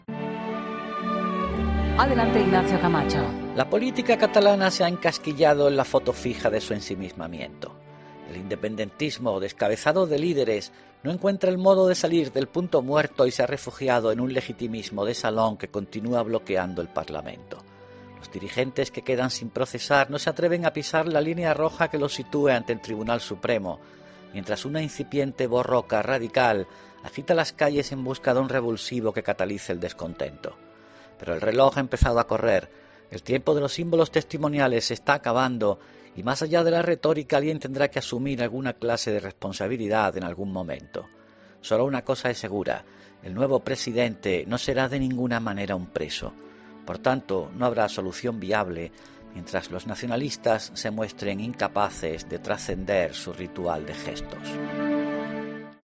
Ignacio Camacho habla en 'La Linterna' de la situación en Cataluña